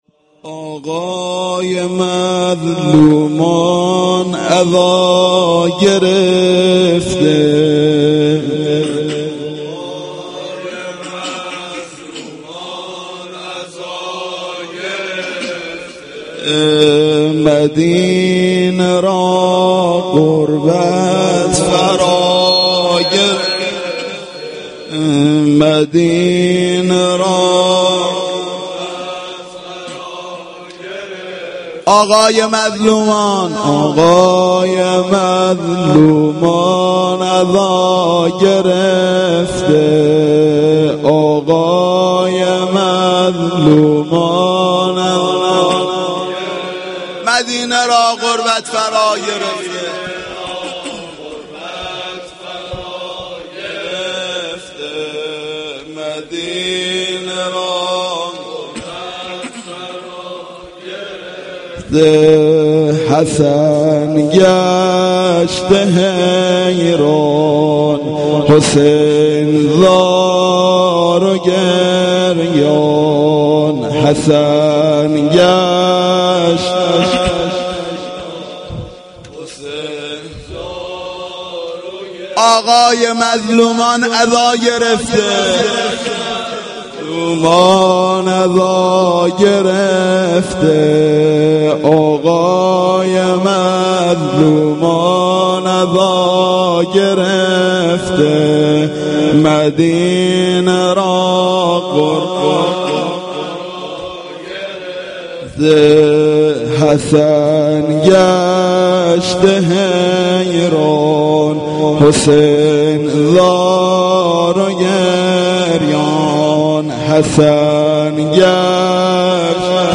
fa-dovom-93-sh1-noheh.mp3